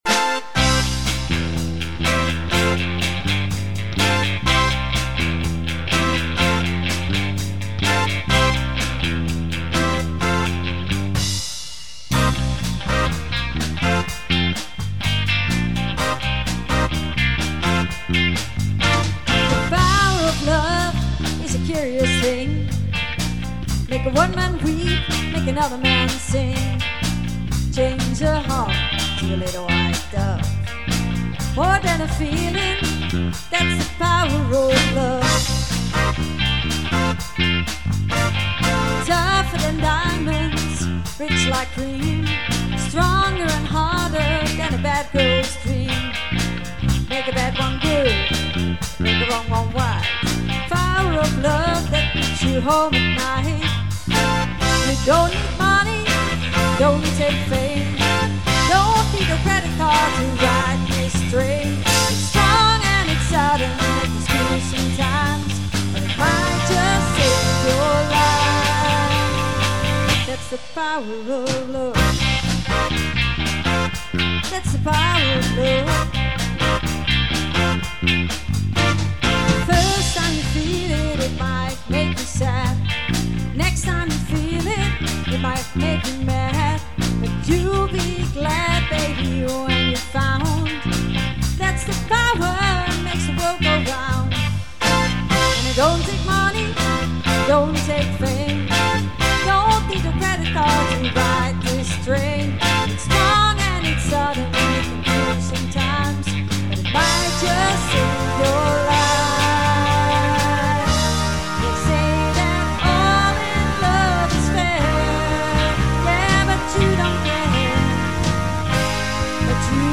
GITAAR & ZANG
DRUMS
LEAD VOCALS
TOETSEN
BASS
80s High Energy